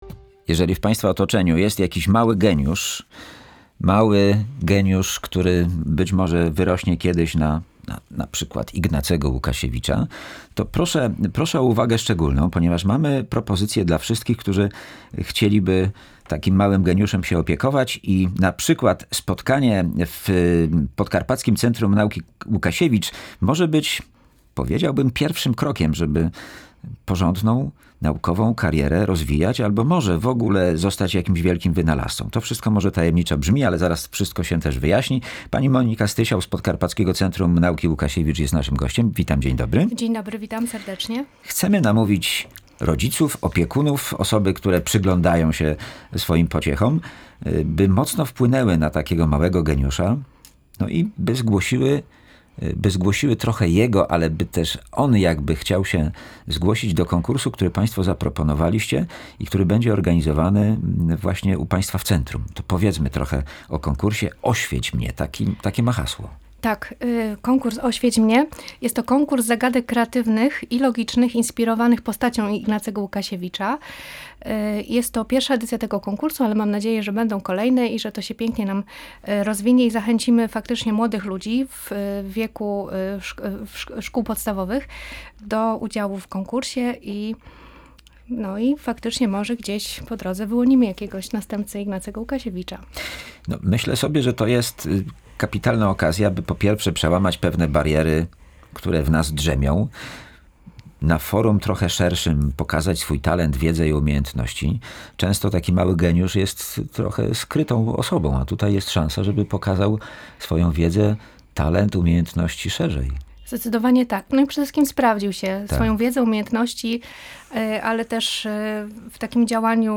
Nasz Gość